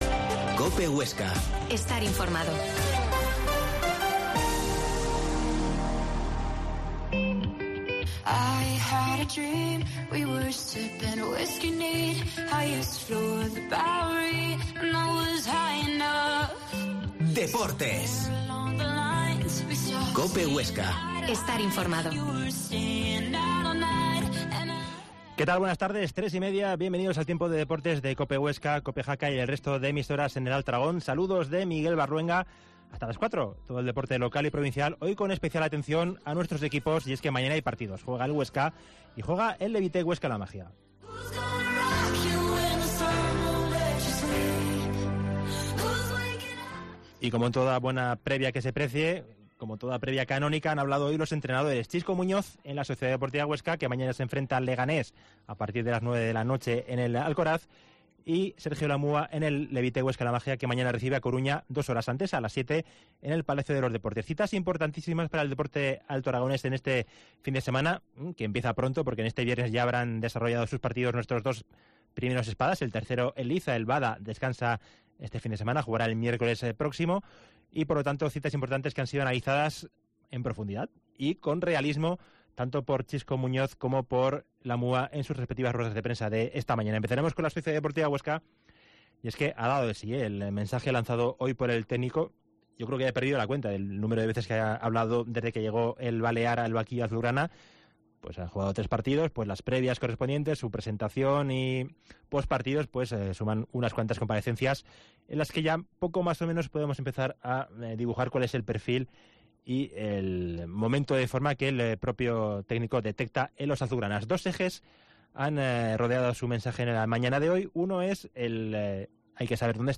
Emisión del programa de hoy con la actividad del Huesca y la rueda de prensa de Xisco Muñoz